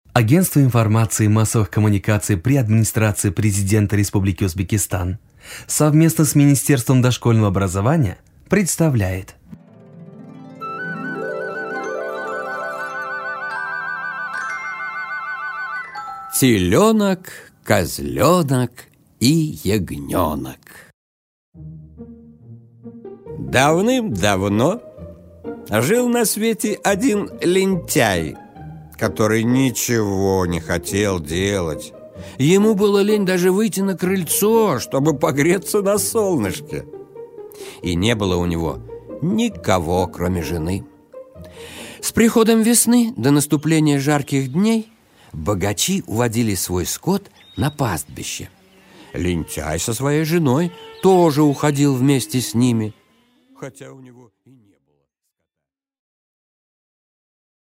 Аудиокнига Телёнок, козлёнок и ягнёнок | Библиотека аудиокниг